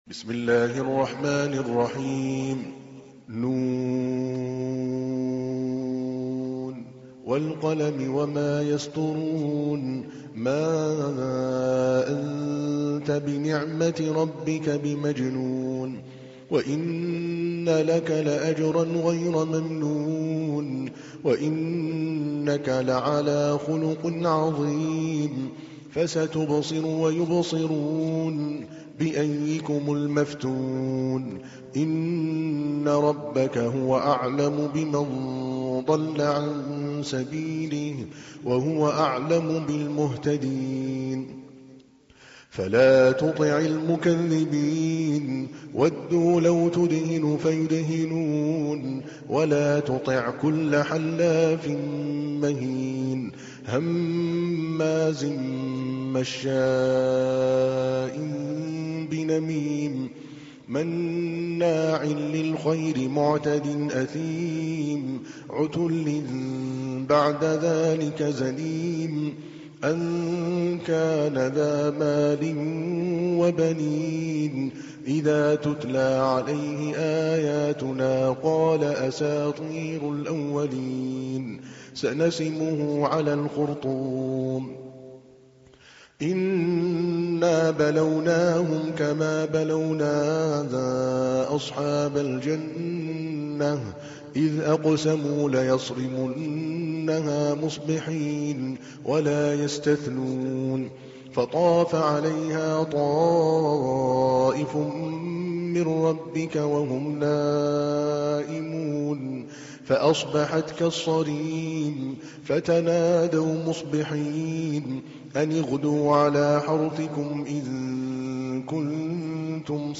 تحميل : 68. سورة القلم / القارئ عادل الكلباني / القرآن الكريم / موقع يا حسين